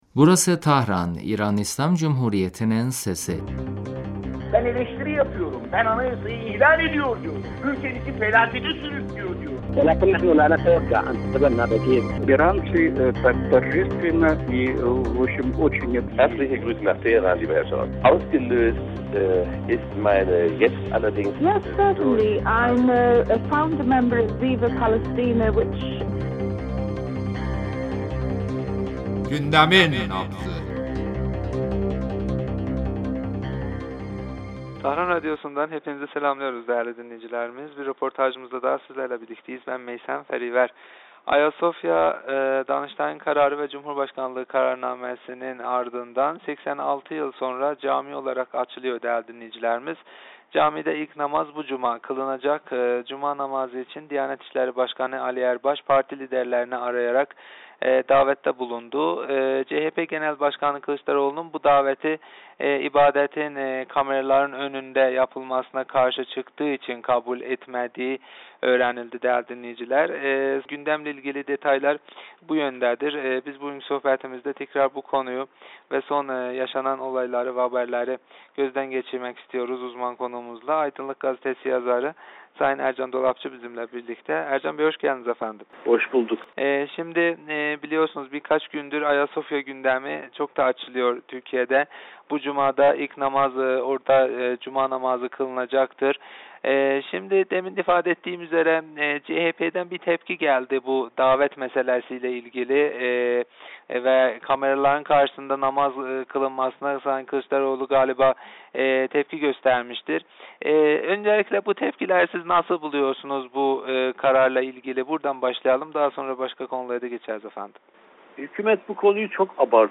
telefon görüşmesinde